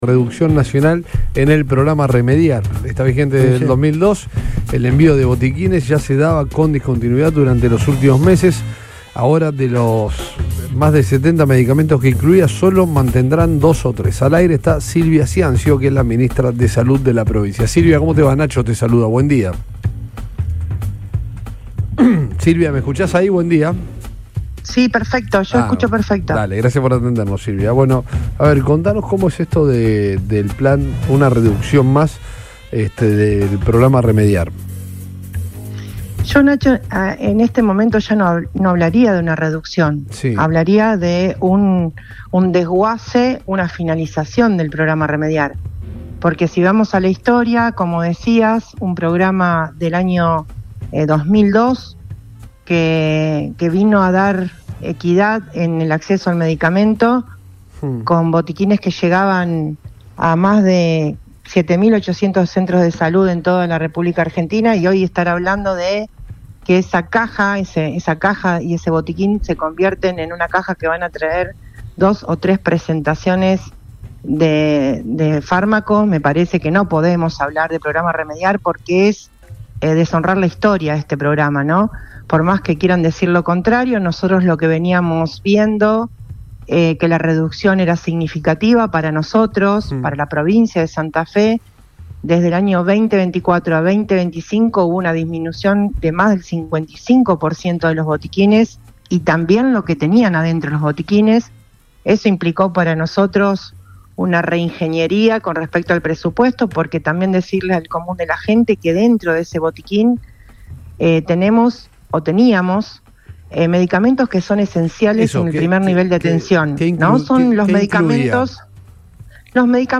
En diálogo con el programa Todo Pasa por Radio Boing, la funcionaria sostuvo que la decisión del Gobierno nacional implica un fuerte retroceso en el acceso a la salud pública, especialmente para los sectores más vulnerables.